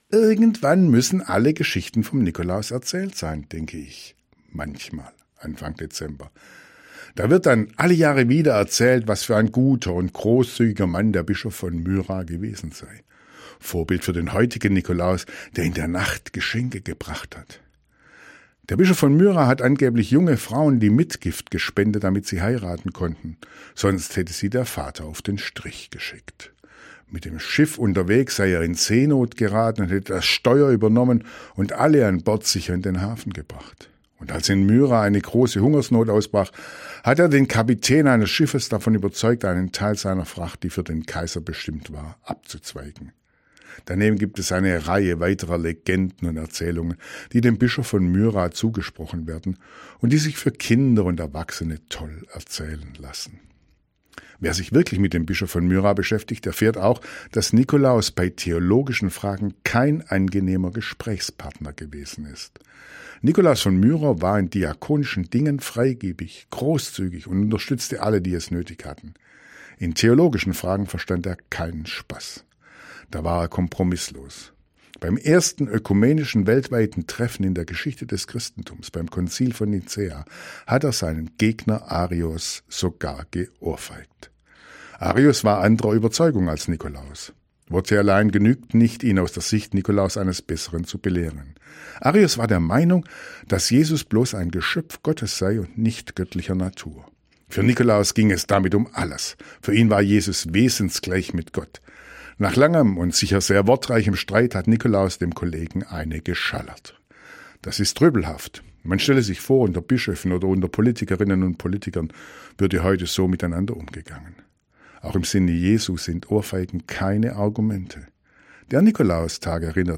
Radioandacht vom 6. Dezember